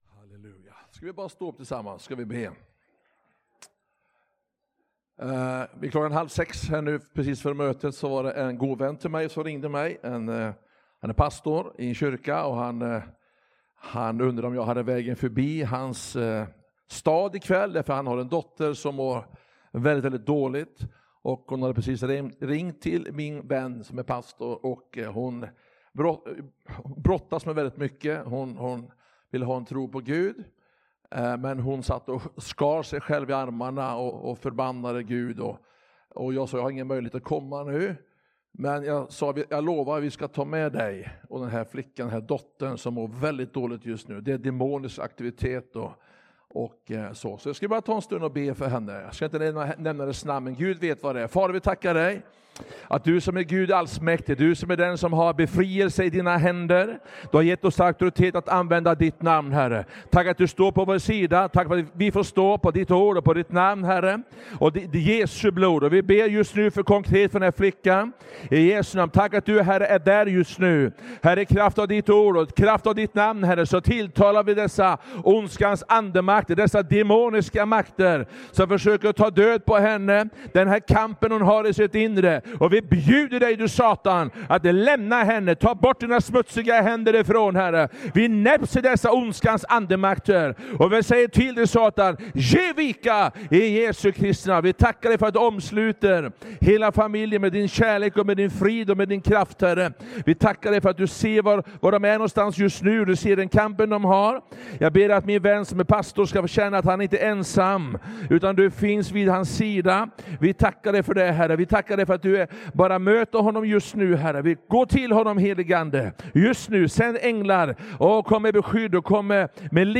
Möteshelg
Korskyrkan Alingsås fredag 26 september 2025